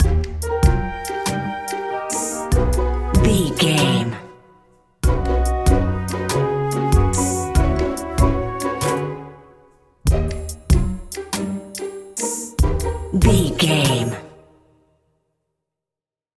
Ionian/Major
orchestra
strings
percussion
flute
silly
circus
goofy
comical
cheerful
perky
Light hearted
quirky